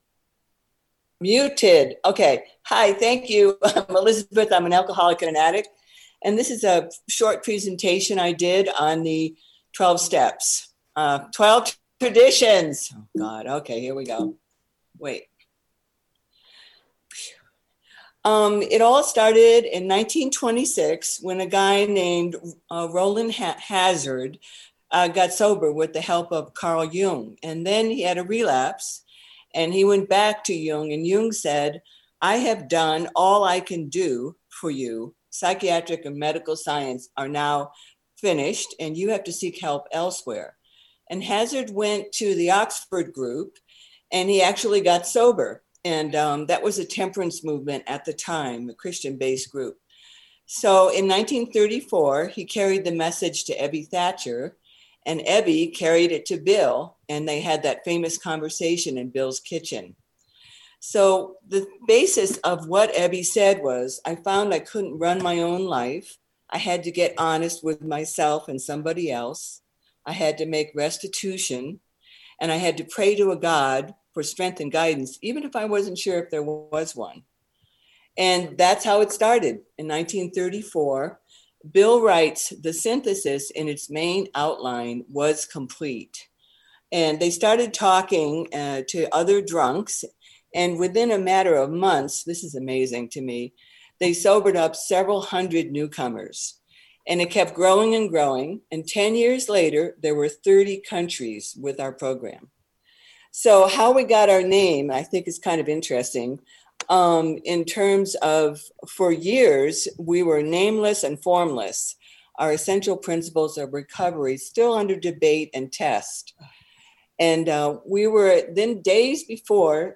CNIA DISTRICT 40 41 42 AND 43 TRADITIONS WORKSHOP